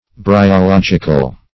Bryological \Bry*o*log"i*cal\, a.